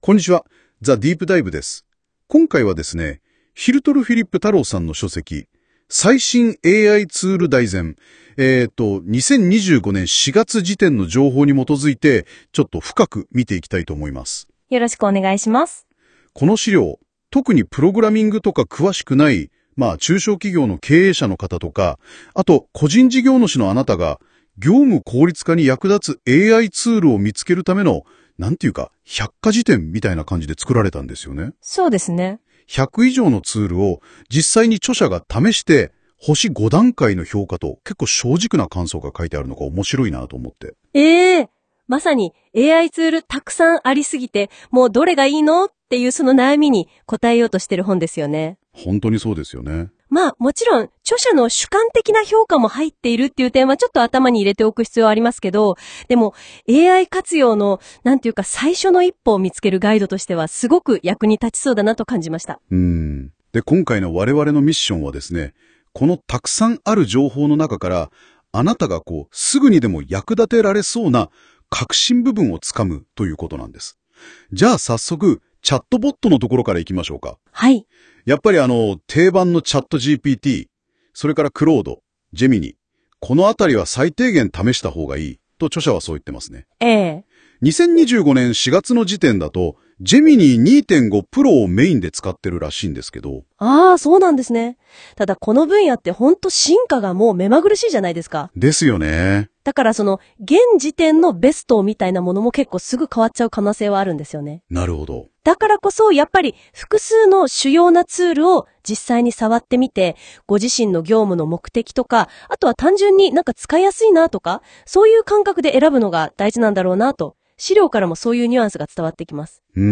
あなたがNotebookLMに読み込ませた資料の内容を、なんとAIがまるでラジオのパーソナリティかのように、音声で分かりやすく解説してくれるんです！
単に文章を読み上げるだけでなく、男性と女性のAIが対話形式で、資料の内容を解説してくれるようなスタイルなんです。
• 漢字の読み間違い: 時々、「えっ？」と思うような漢字の読み間違いがあって、話の内容が分かりにくくなることがありました。
• 話し方のぎこちなさ: AI同士の会話形式なのですが、特に驚きを表す「えー！」みたいな部分が、少しロボットっぽいというか、ぎこちなく聞こえることがありました。
実際にNotebookLMが生成した日本語音声はこちら↓